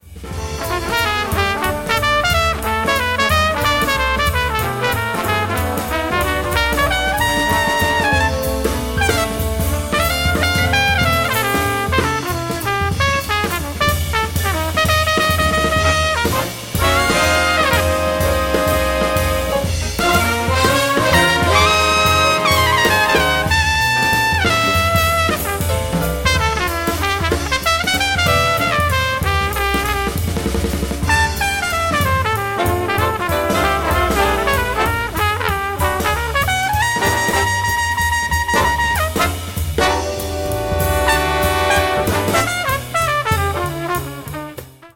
Then imply 4/4  and eventually get there.